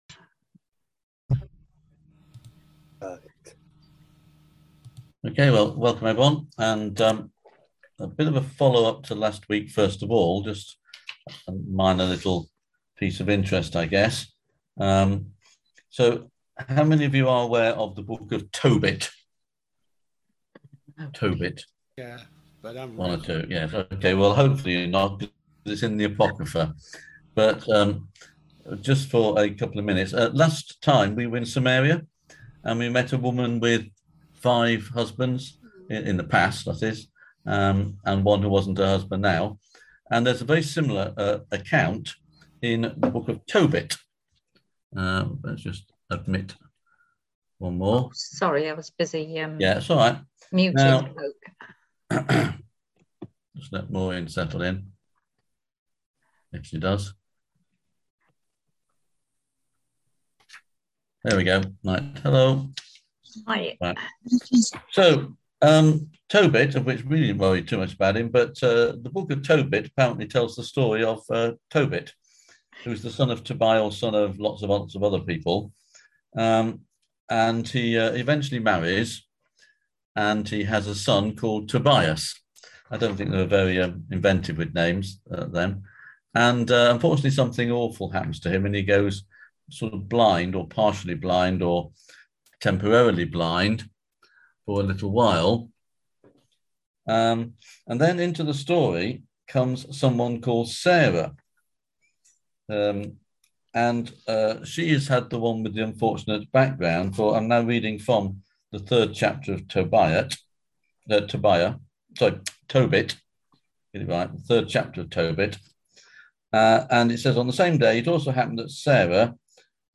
On June 16th at 7pm – 8:30pm on ZOOM